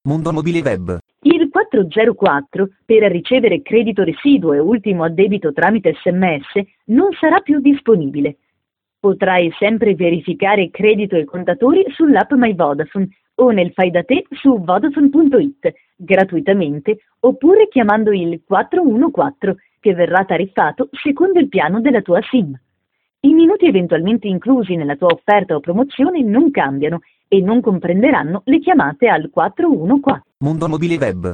Ecco il file audio (registrato in esclusiva per voi) chiamando il numero gratuito 42598 (digitando il tasto 1):